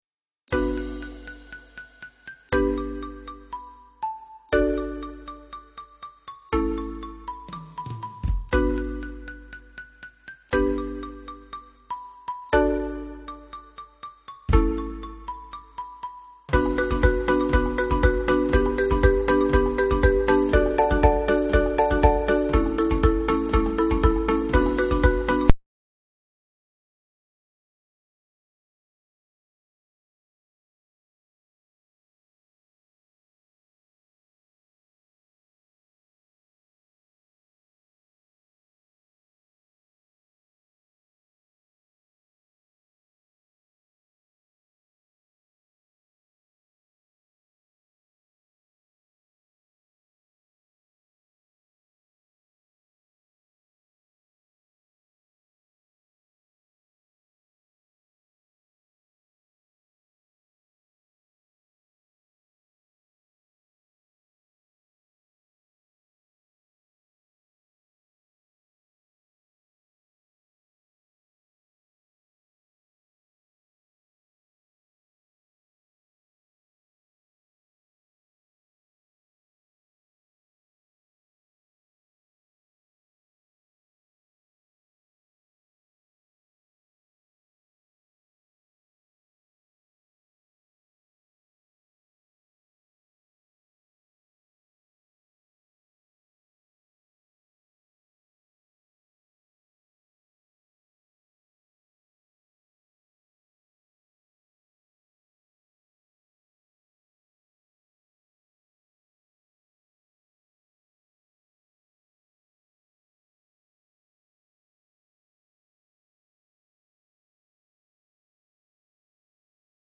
Mallet-Steelband Muziek
Xylofoon Marimba Drumset Bongo's Timbales